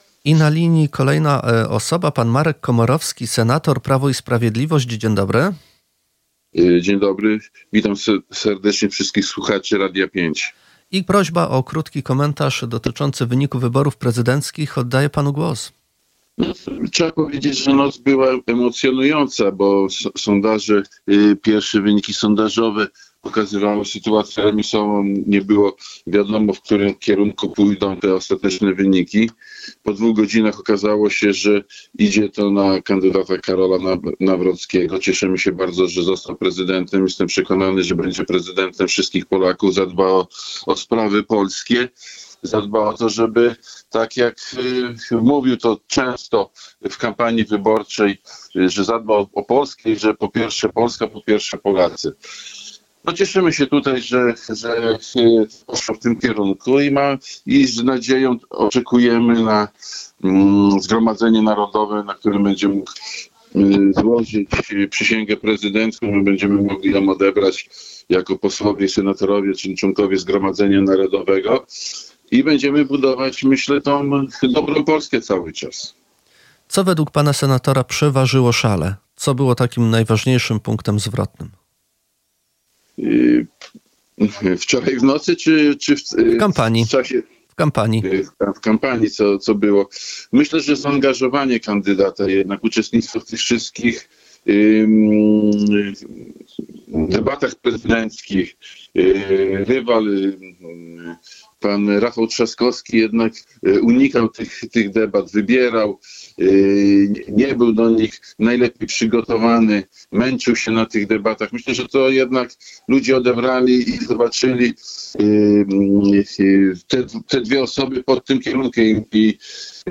– Karol Nawrocki będzie prezydentem wszystkich Polaków i zadba o sprawy polskie – powiedział w rozmowie z Radiem 5 Marek Komorowski, senator Prawa i Sprawiedliwości.